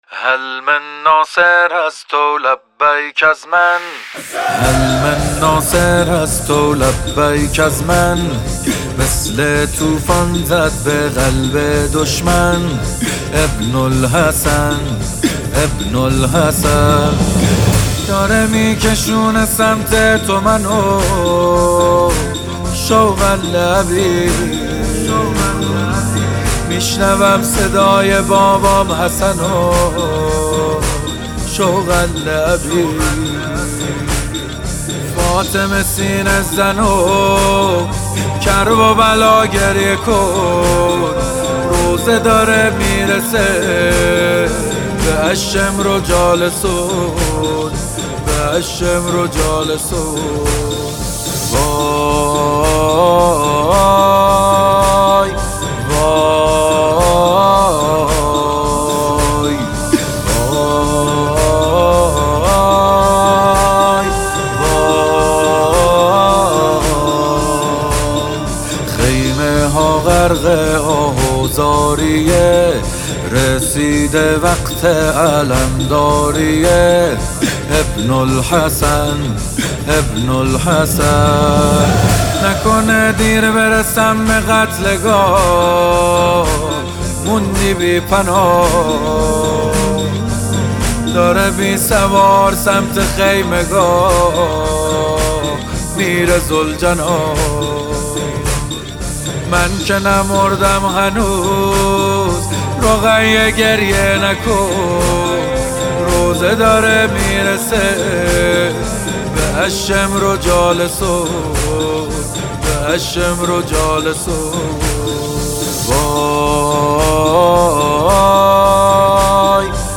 نماهنگ مذهبی
نماهنگ ویژه شب ششم محرم